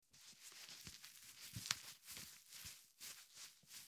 Звуки тампона
На этой странице собраны звуки тампона в разных вариациях.
Готовишься использовать тампон